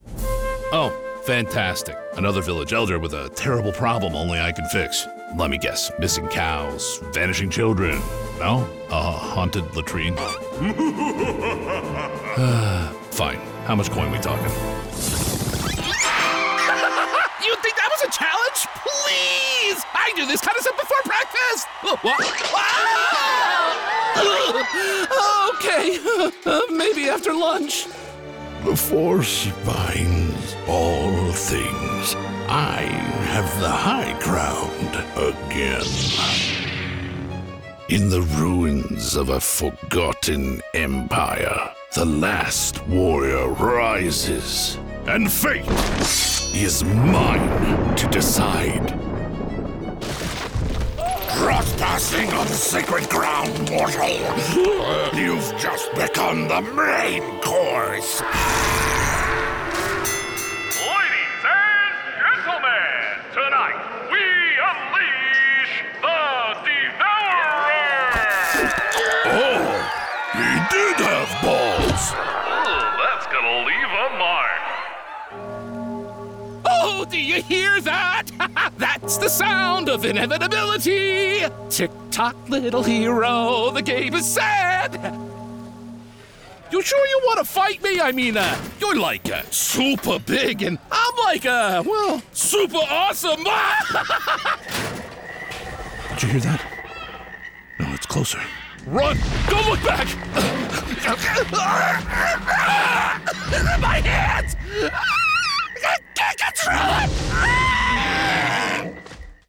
Native Voice-Samples
Videospiele
Sennheiser MKH 416 Richtmikrofon
Neumann TLM-103 Kondensatormikrofon